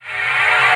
VEC3 Reverse FX
VEC3 FX Reverse 26.wav